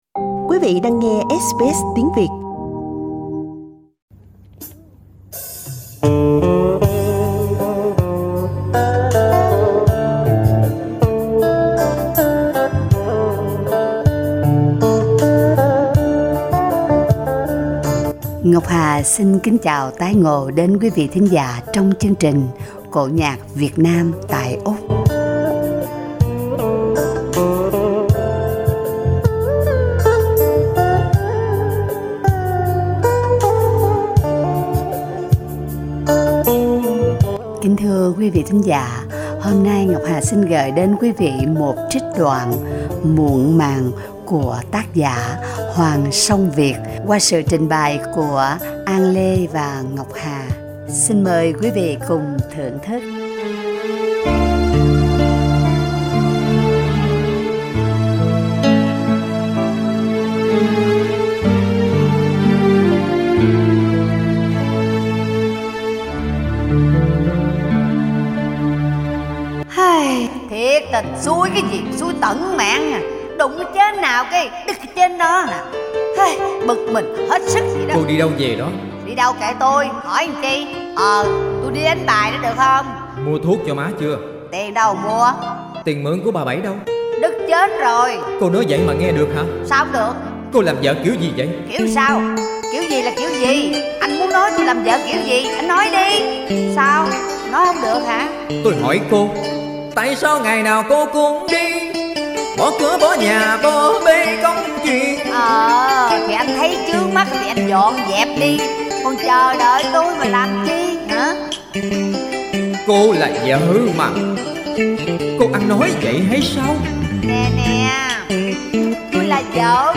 trích đoạn cải lương ngắn
bài ca cổ